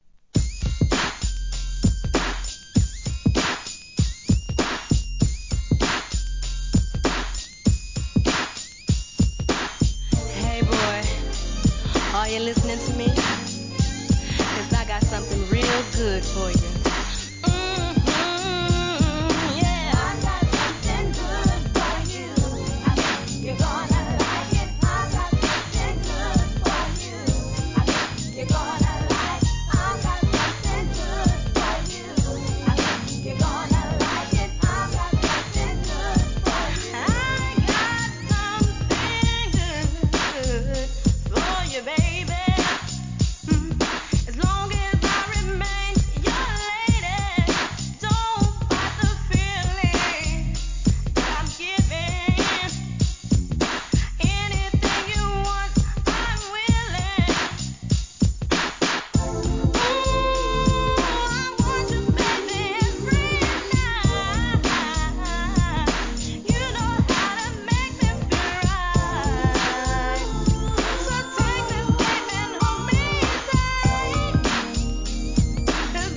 HIP HOP/R&B
西海岸産怒マイナーR&B!! FUNKトラックにシンセ使いがWESTファン、ツボ!!